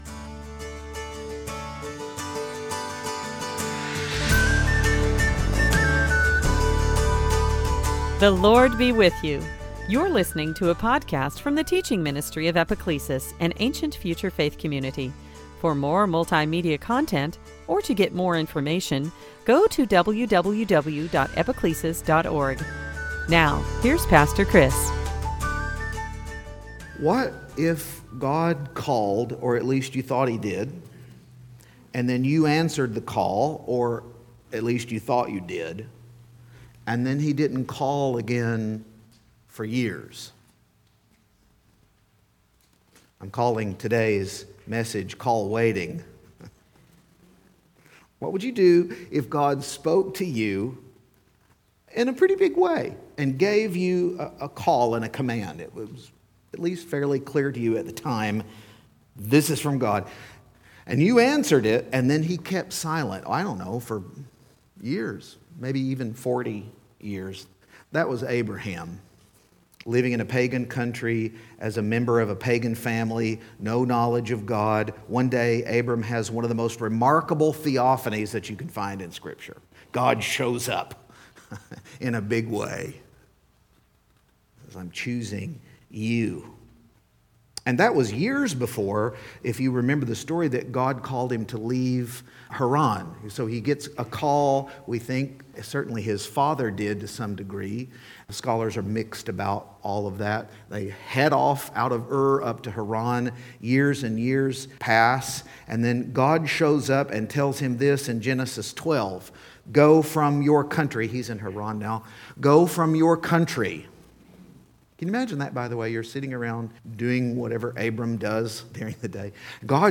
Series: Sunday Teaching
Service Type: Lent